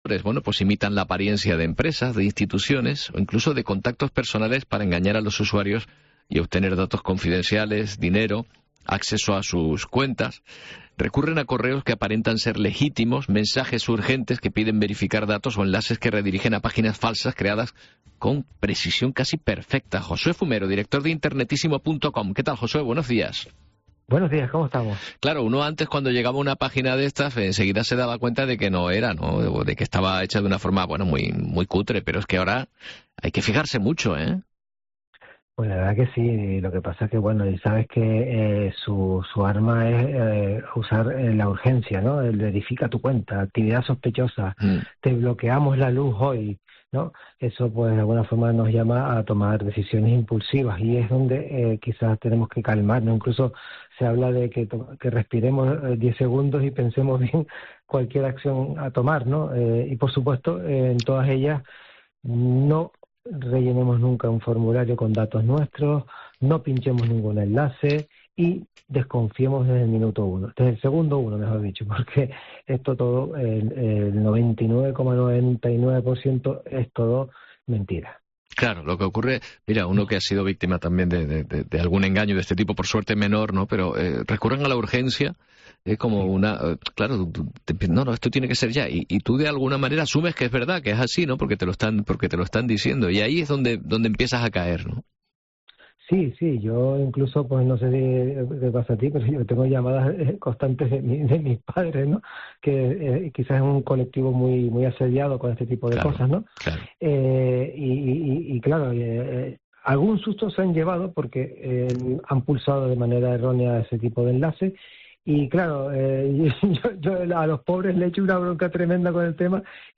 Esta semana, en el programa La mañana de COPE Canarias, hablamos sobre este riesgo y cómo podemos protegernos